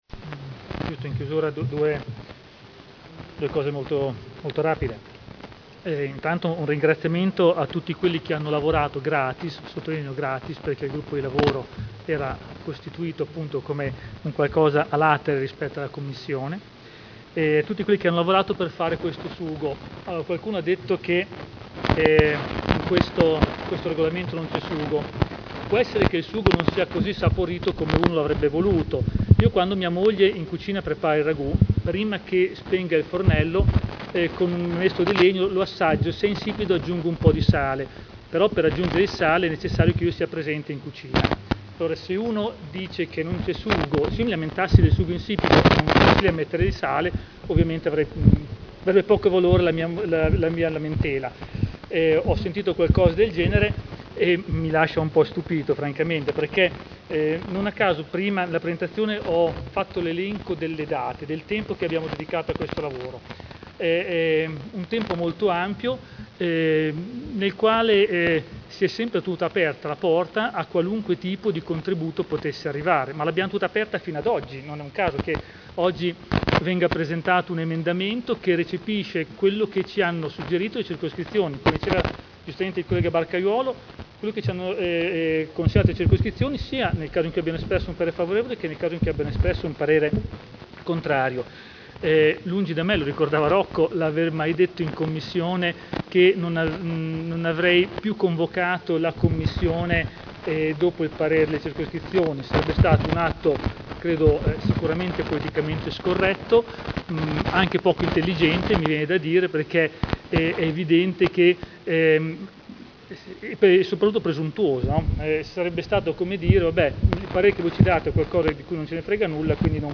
Seduta del 14/07/2011. Dibattito su delibera: Regolamento dei Consigli di Circoscrizione – Modifica (Commissione Affari Istituzionali del 13 maggio 2011 e del 6 luglio 2011)